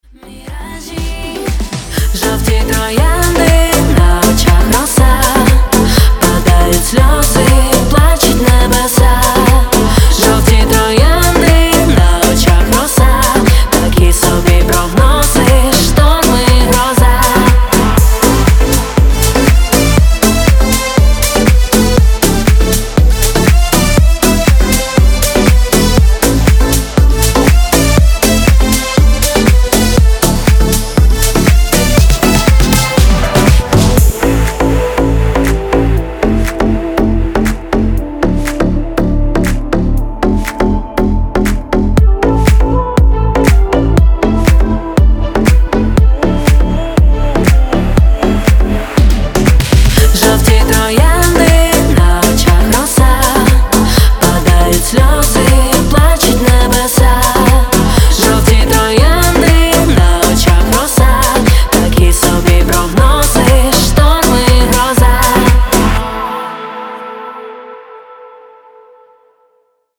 • Качество: 320, Stereo
поп
зажигательные
красивый женский голос
Украинская поп музыка